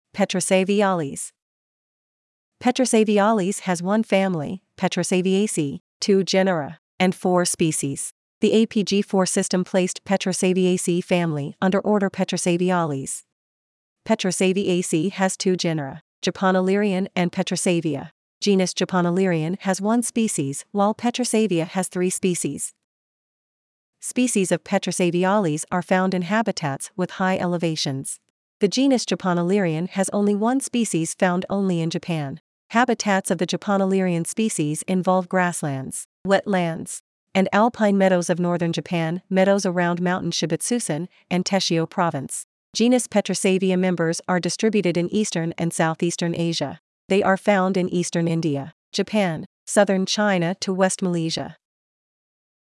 Petrosaviales-Pronunciation.mp3